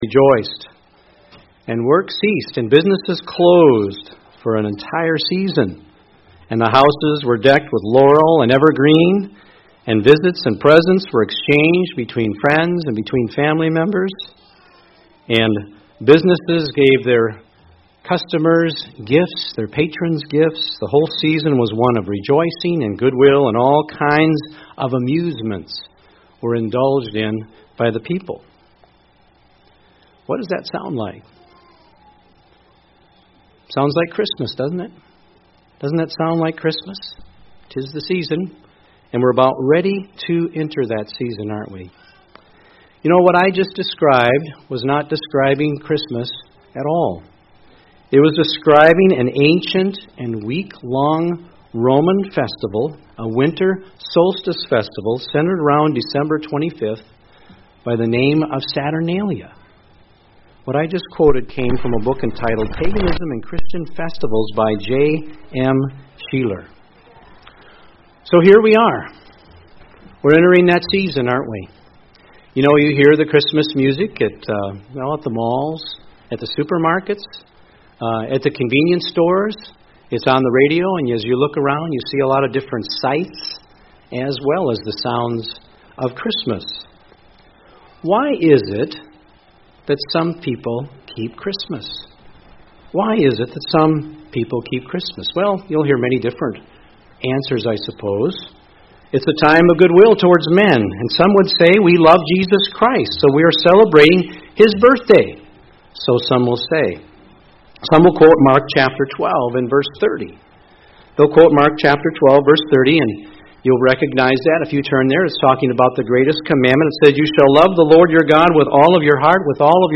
UCG Sermon Christmas Worship Transcript This transcript was generated by AI and may contain errors.